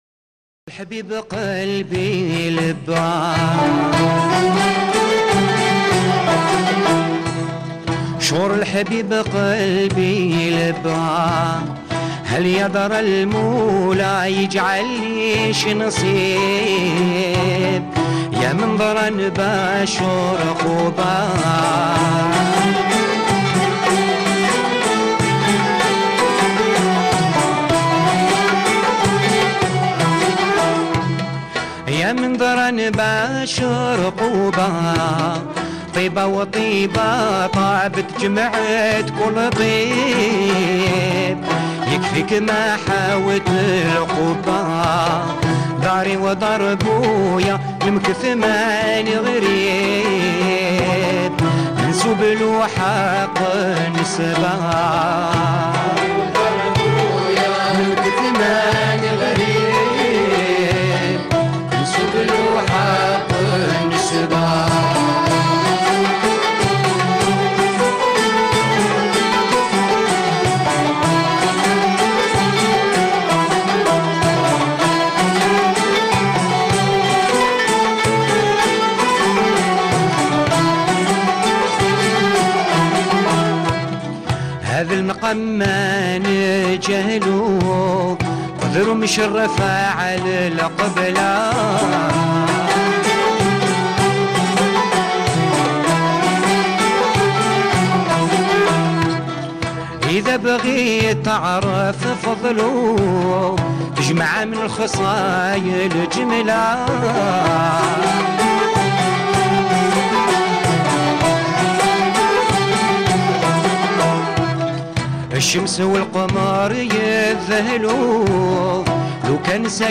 -5- طرب غرناطي